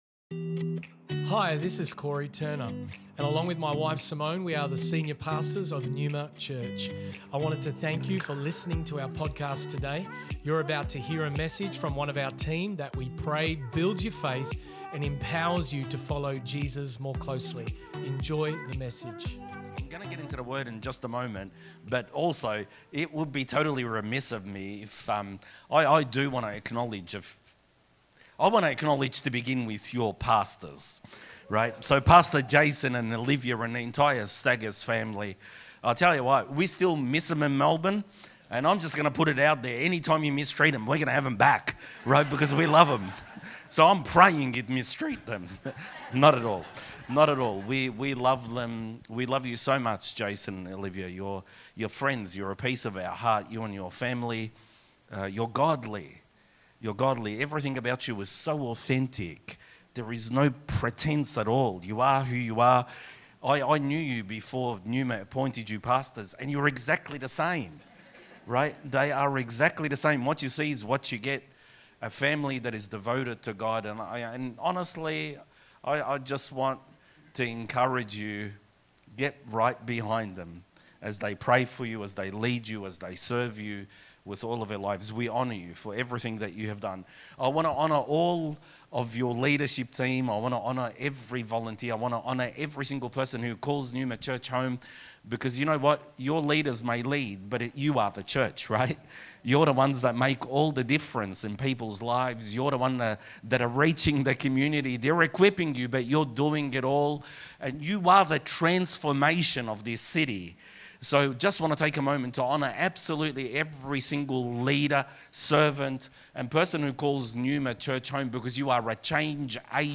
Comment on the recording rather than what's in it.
One Year Celebration Service